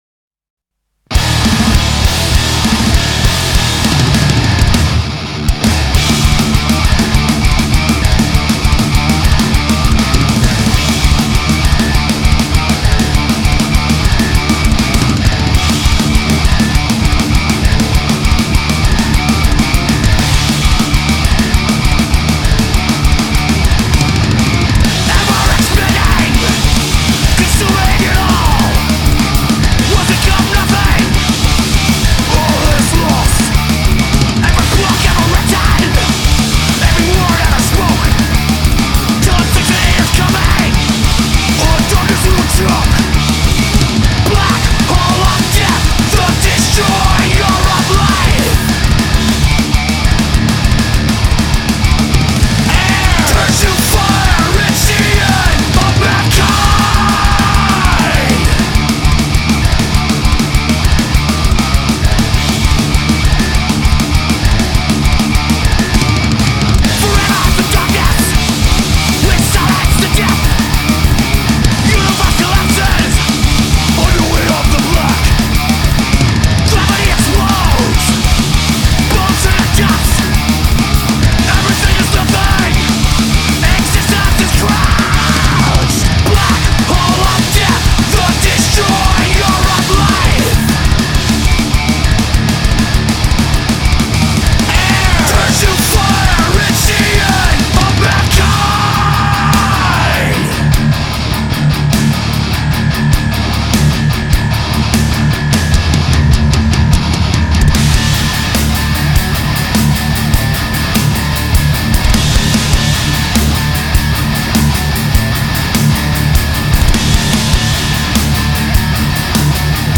Трек размещён в разделе Зарубежная музыка / Метал.